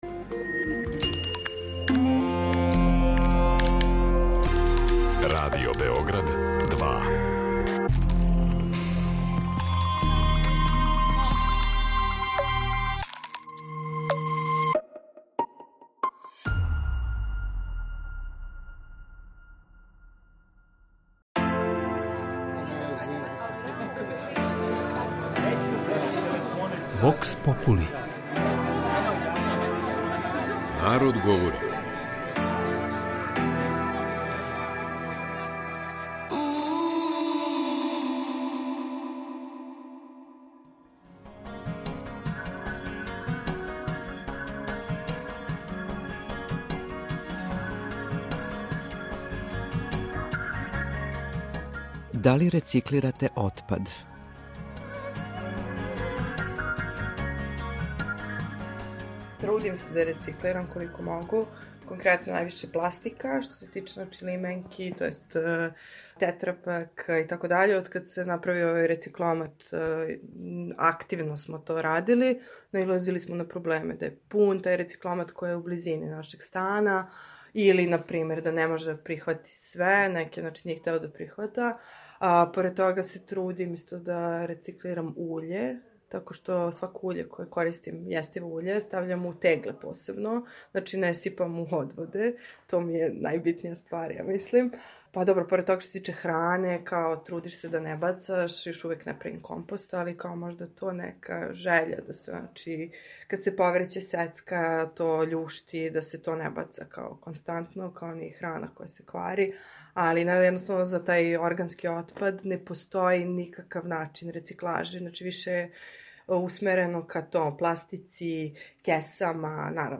Вокс попули